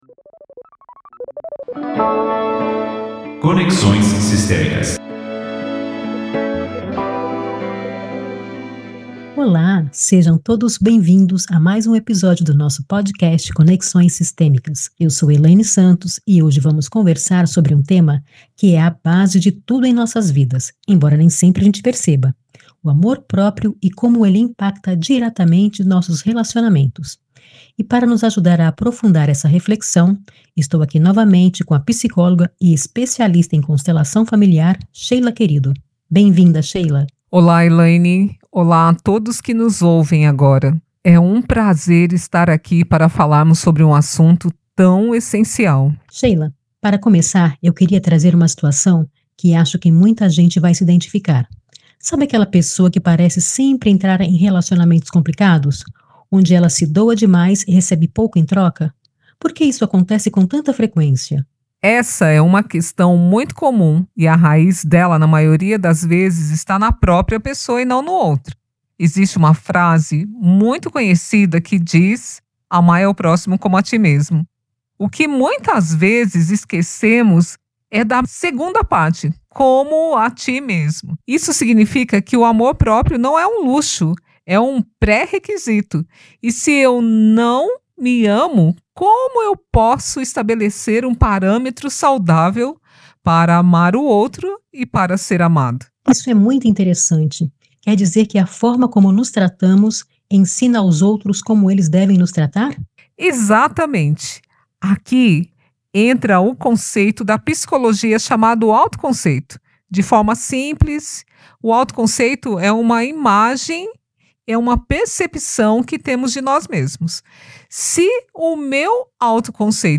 Descubra como o seu autoconceito molda suas relações e aprenda os primeiros passos para quebrar padrões negativos. Uma conversa para quem busca o autoconhecimento e deseja entender como a origem de certas dinâmicas pode estar profundamente ligada ao seu sistema familiar.